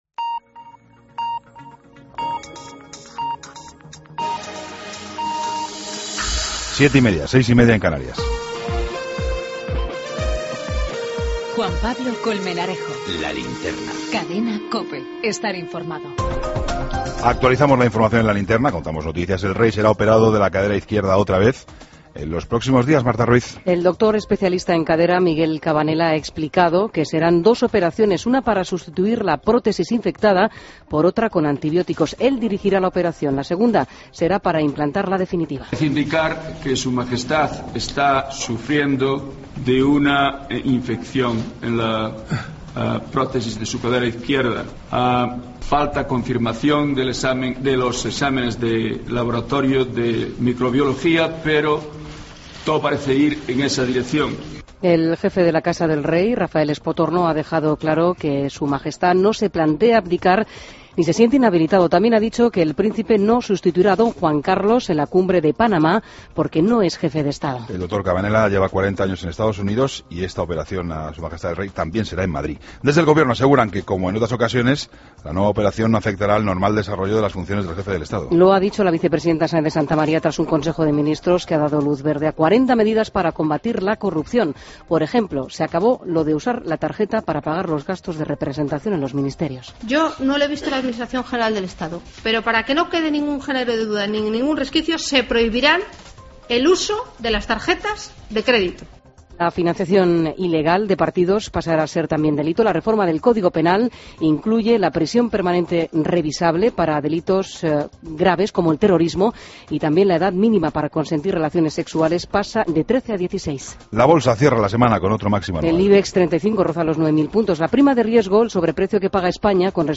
Toda la información con Juan Pablo Colmenarejo
Entrevista